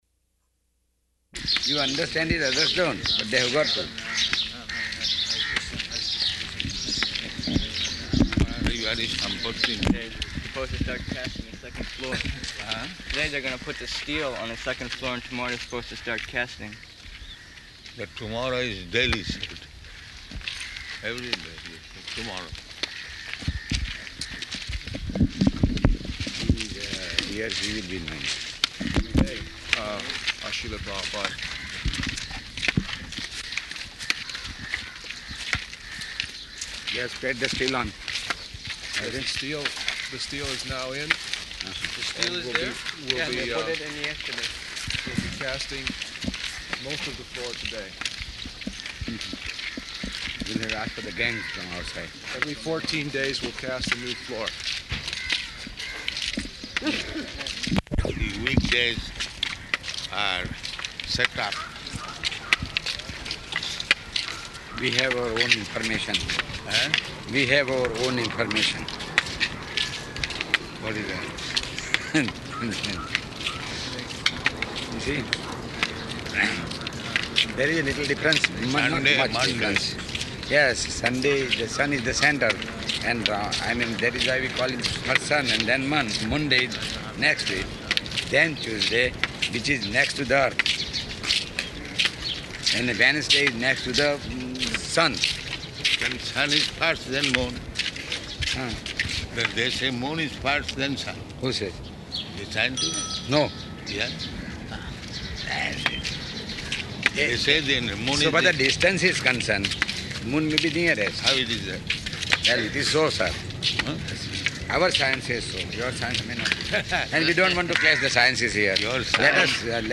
-- Type: Walk Dated: November 19th 1975 Location: Bombay Audio file